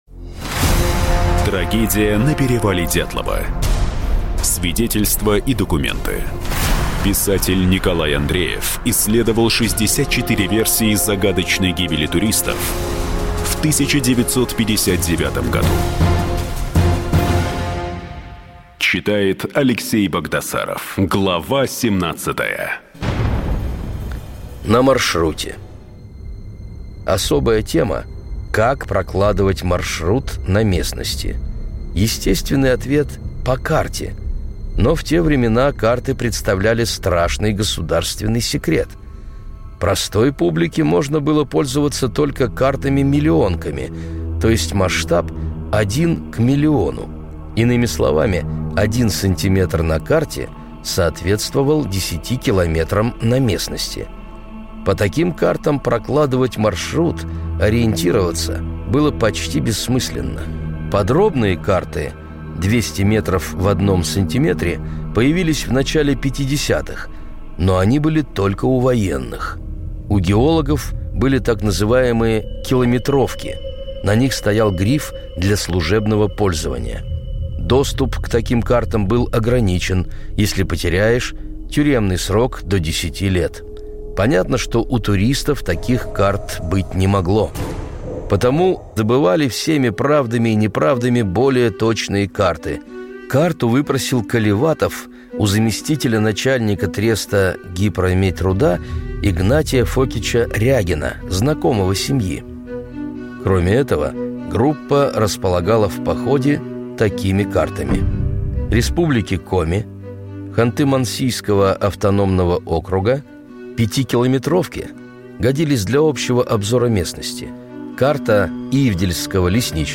Аудиокнига Трагедия на перевале Дятлова: 64 версии загадочной гибели туристов в 1959 году. Часть 17 и 18.